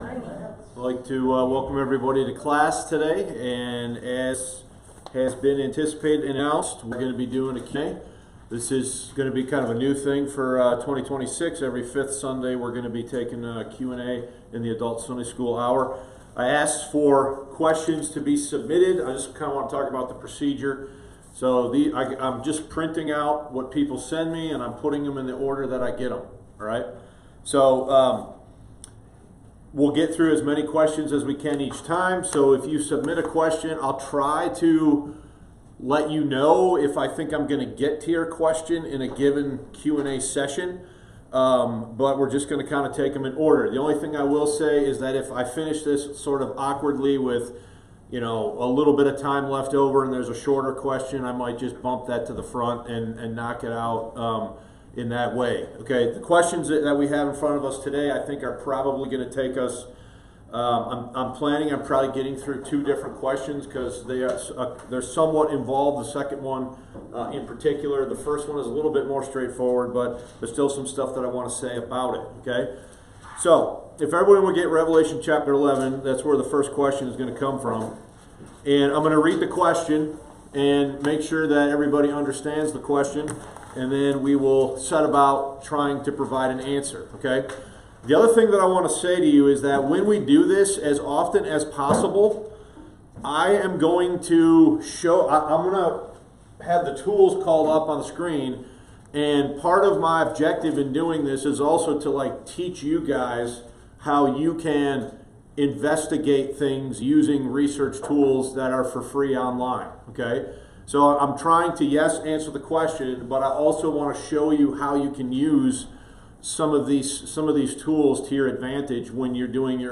Adult Sunday School Q&A: Revelation 11:15 & What Gospel Did Peter Preach?